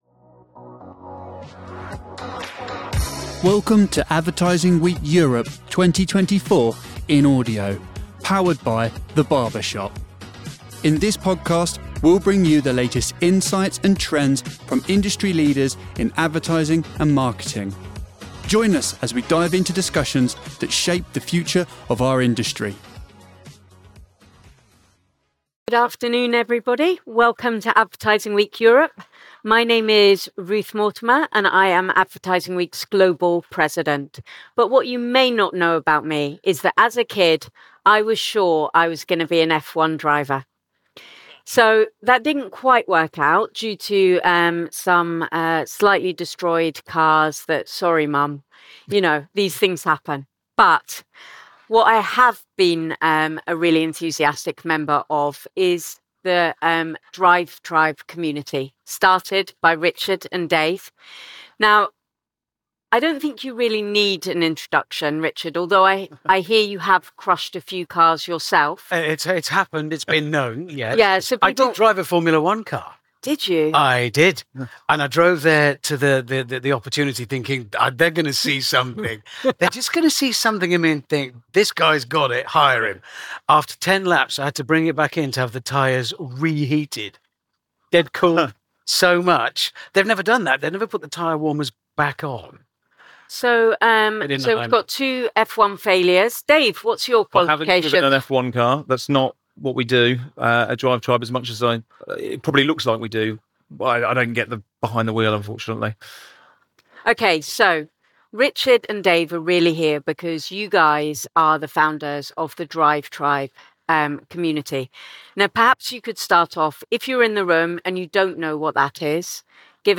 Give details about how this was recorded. Discover how brands can use storytelling to build and sustain engaged communities, transitioning stories from online to offline, and implementing marketing strategies that resonate with audiences. This session delves into the power of narrative in creating compelling and lasting connections.